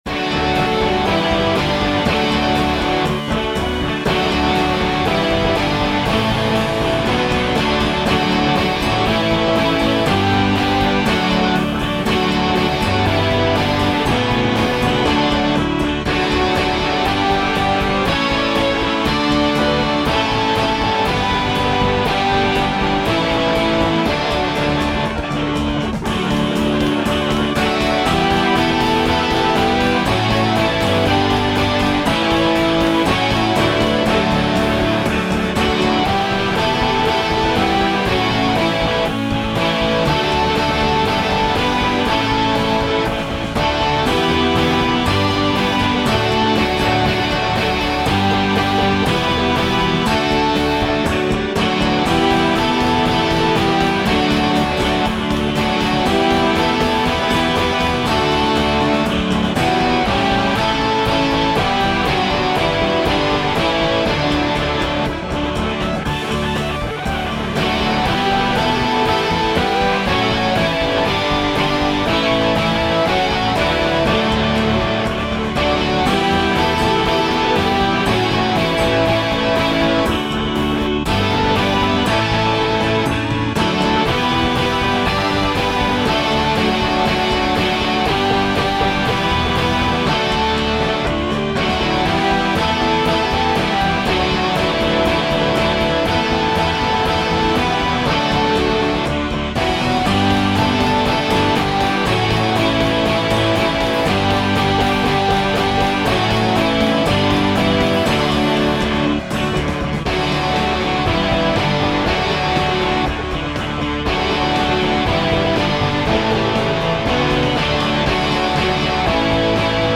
midi-demo 2